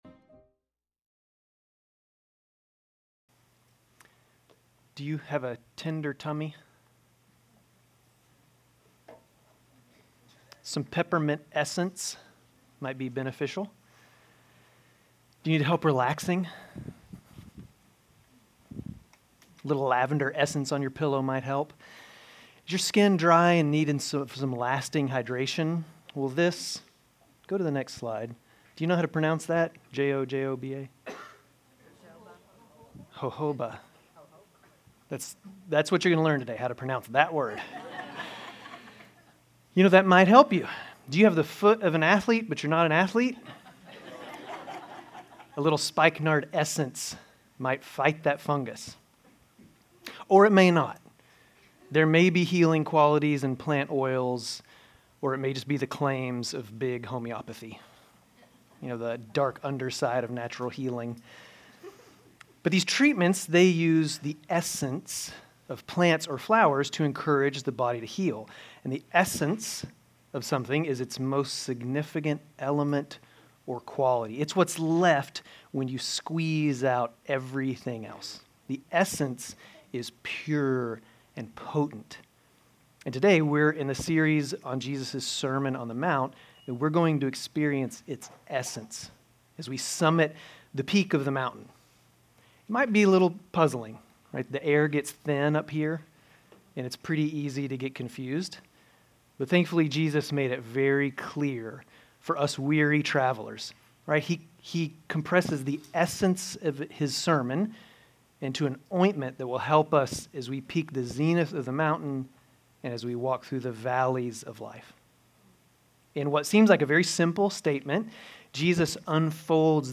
Grace Community Church Dover Campus Sermons 3_2 Dover Campus Mar 03 2025 | 00:28:17 1x 00:00 / 00:28:17 Subscribe Share RSS Feed Share Link Embed